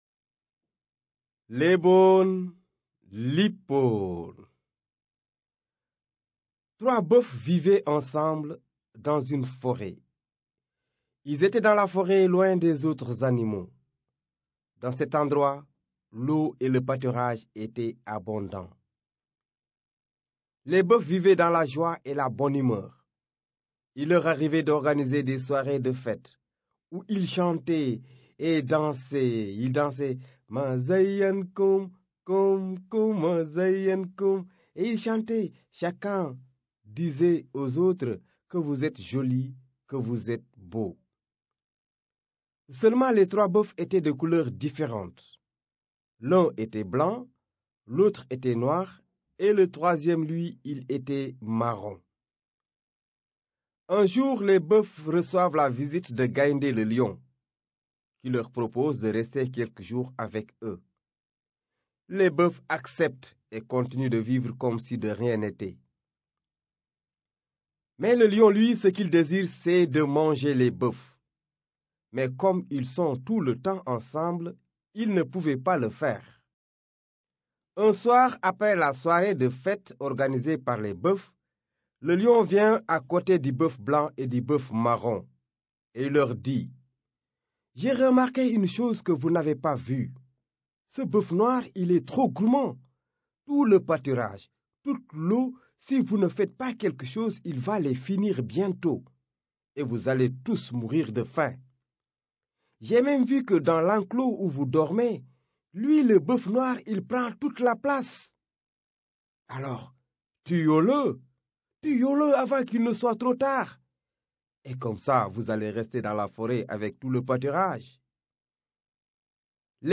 Découvrez les contes traditionnels de Mauritanie racontés en français et en wolof.